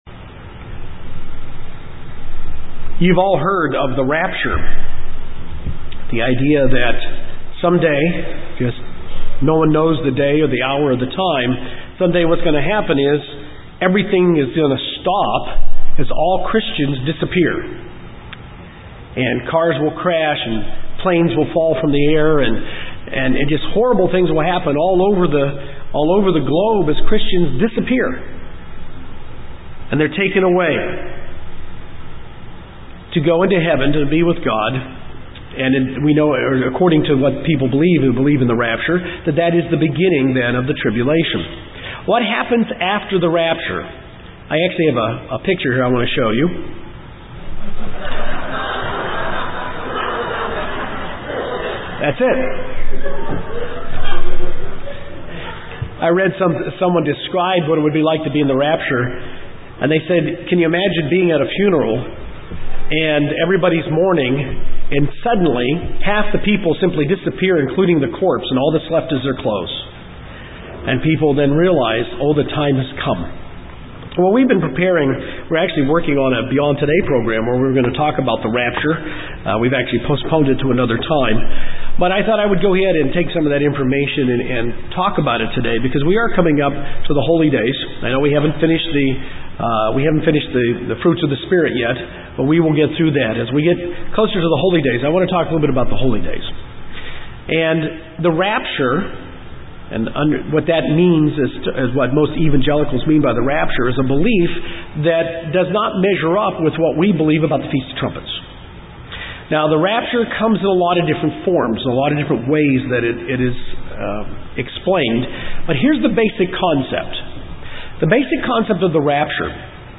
In this sermon we understand why it is important that we understand the truth about Christ’s return.